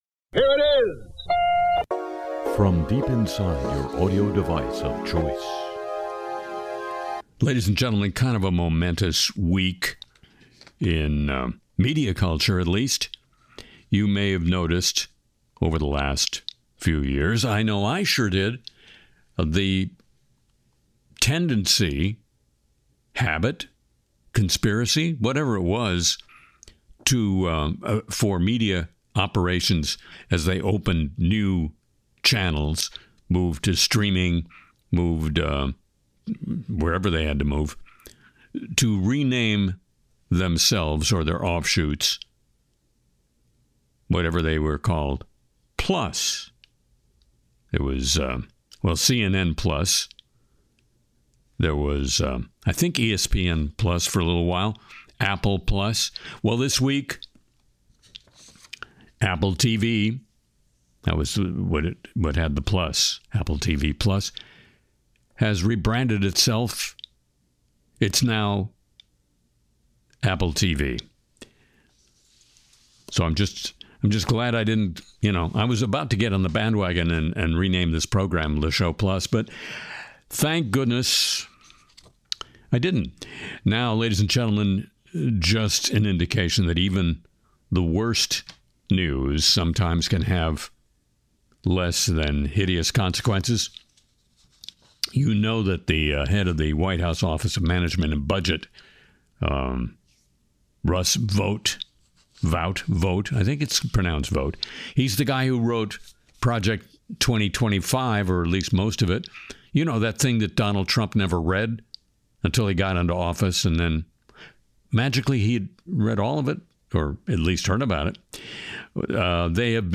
Harry mocks Tom Homan in a new parody segment, digs into AI bubble fears, no one will pay for ChatGPT, soaring AI power bills, Sora 2’s problems, and a global coral die-off warning.